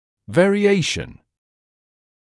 [ˌveərɪ’eɪʃn][ˌвэари’эйшн]варьирование; вариация, изменчивость; изменение